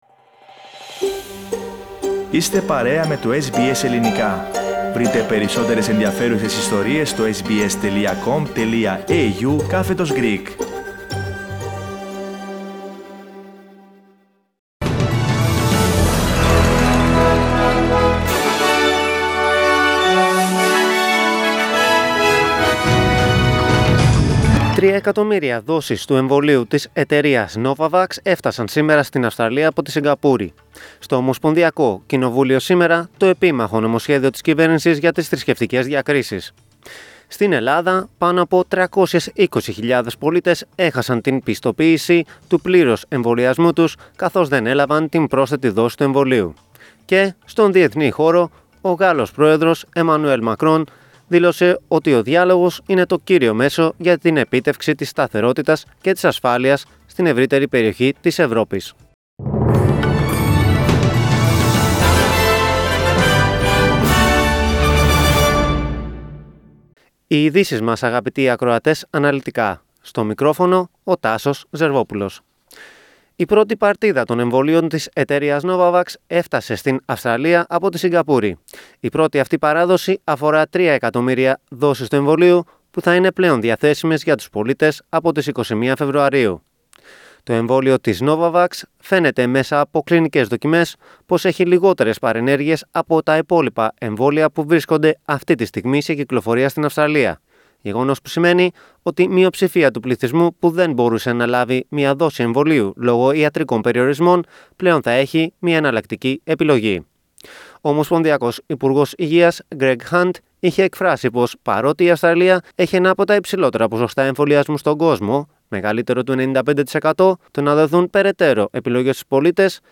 Δελτίο Ειδήσεων 8.2.22